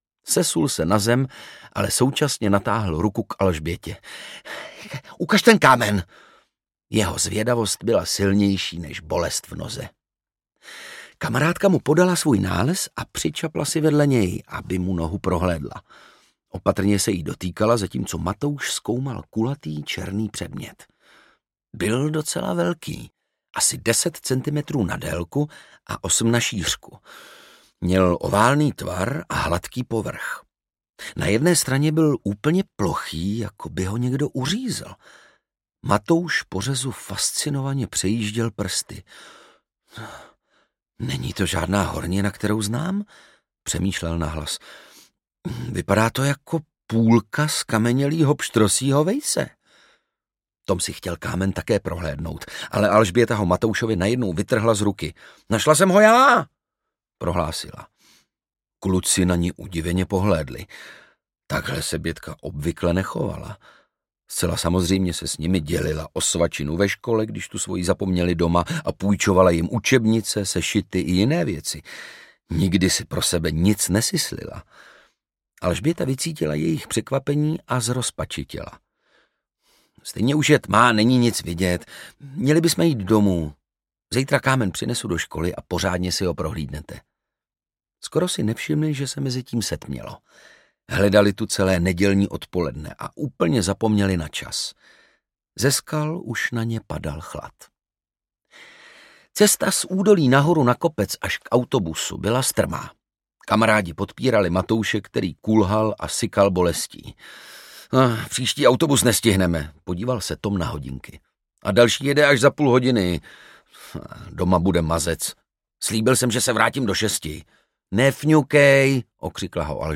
Meteorit z Mušlovky audiokniha
Ukázka z knihy
Čte Ondřej Brousek.